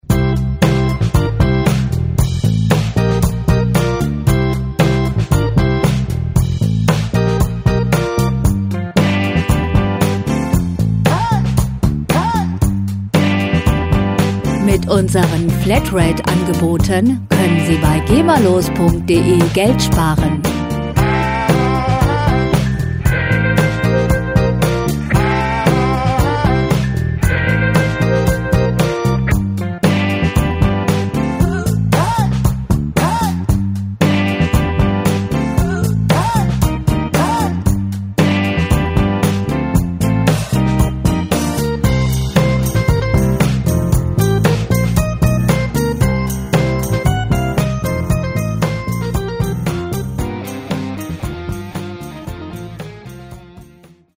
Rockmusik - Legenden
Musikstil: Sixties Soul
Tempo: 115 bpm
Tonart: C-Dur
Charakter: entschlossen, soulig